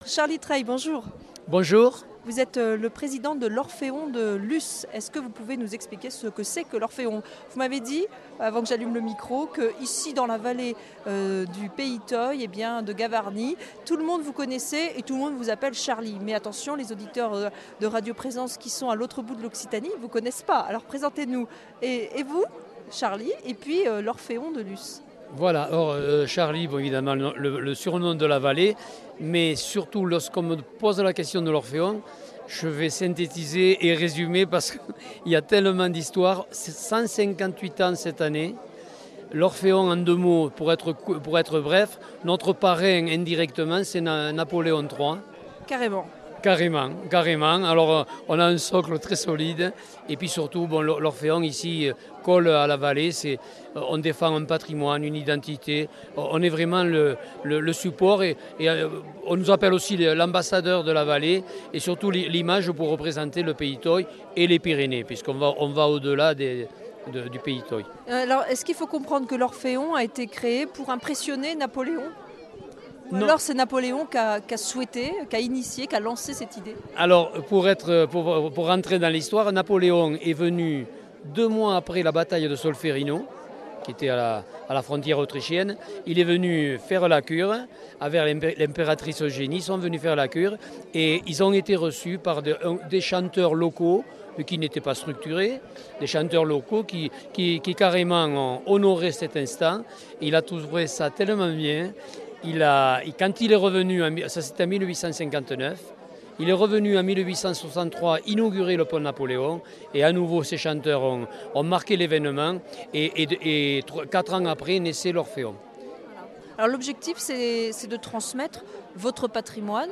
Interview et reportage du 09 sept.